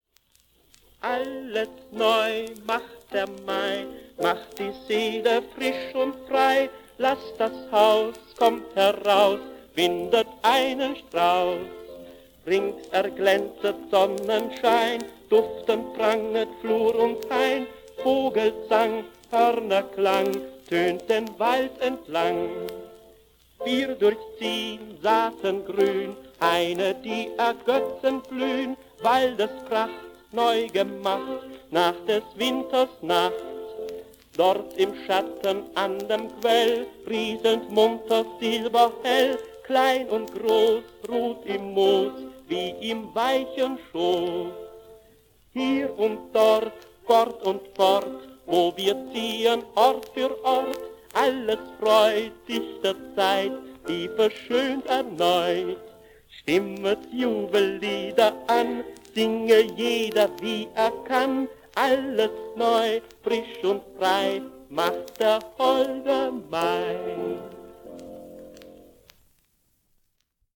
Volks- und Kinderlied